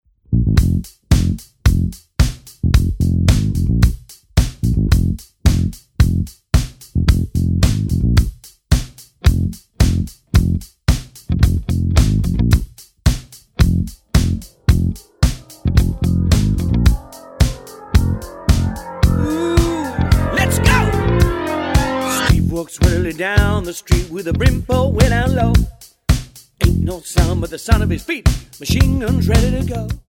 Tonart:F Multifile (kein Sofortdownload.